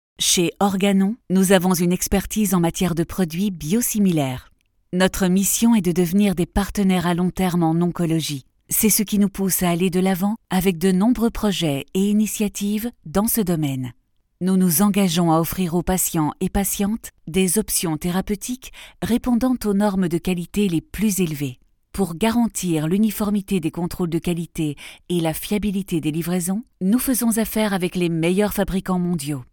Kommerziell, Verspielt, Freundlich, Vielseitig, Sanft
Erklärvideo